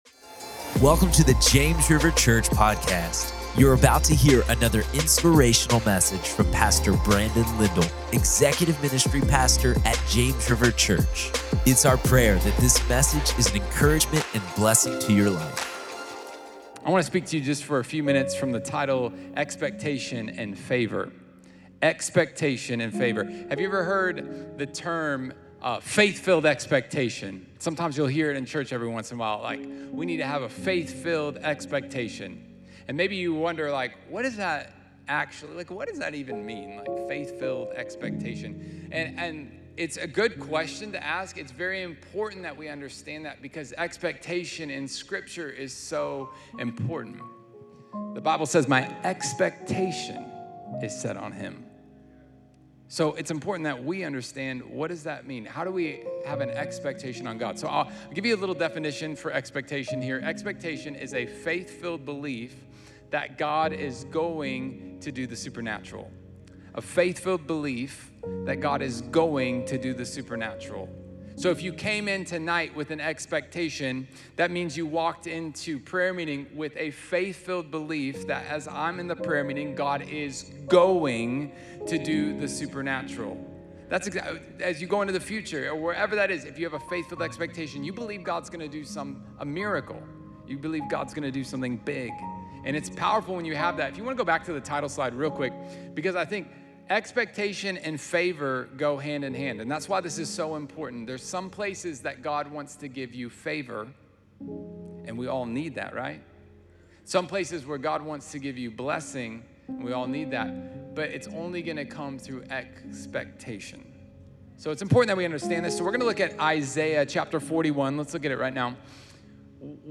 Expectation & Favor | Prayer Meeting | James River Church